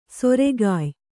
♪ soregāy